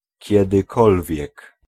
Ääntäminen
Ääntäminen Tuntematon aksentti: IPA: [cɛd̪ɨˈkɔlvʲjɛk] Haettu sana löytyi näillä lähdekielillä: puola Käännös Ääninäyte Pronominit 1. whenever US Adverbit 2. whenever US 3. ever RP GenAm US UK Luokat Pronominit